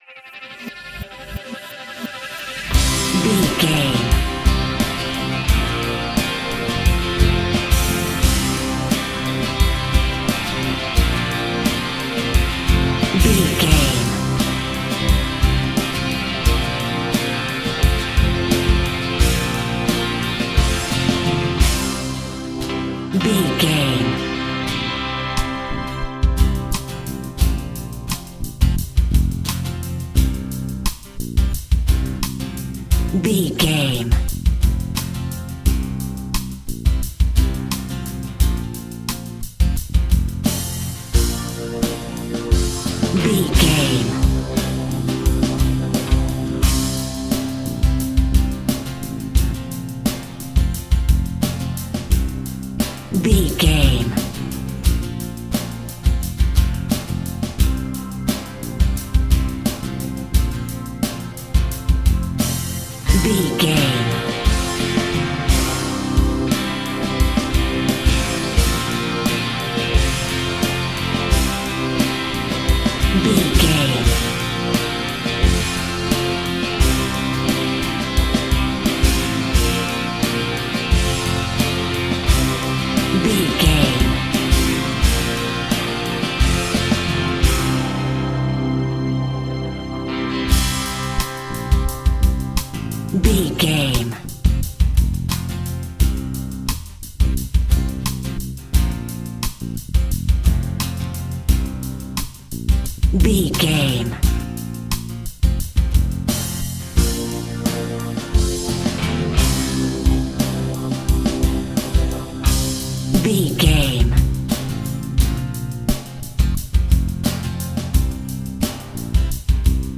Power Rock From The 70s.
Epic / Action
Fast paced
Ionian/Major
heavy rock
blues rock
distortion
hard rock
Instrumental rock
drums
bass guitar
electric guitar
piano
hammond organ